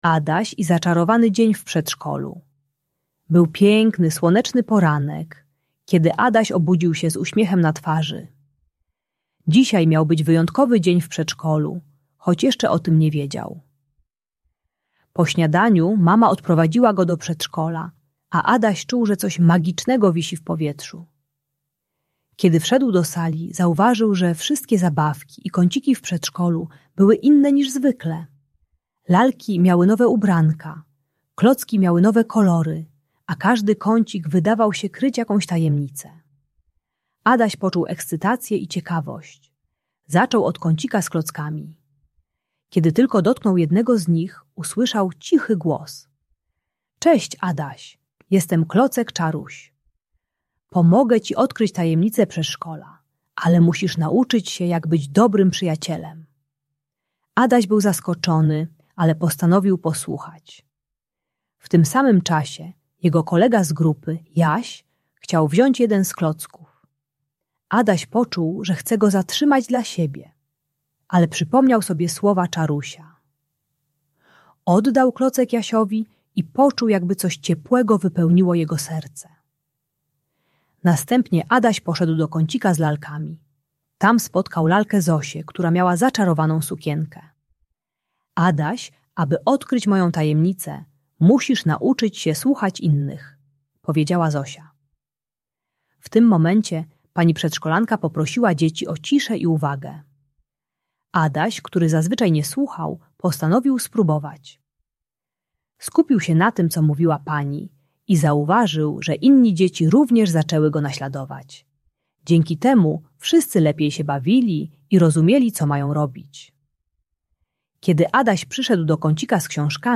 Adaś uczy się techniki głębokiego oddechu i liczenia do 10, gdy czuje złość. Audiobajka o radzeniu sobie z emocjami i dzieleniu się z innymi dziećmi.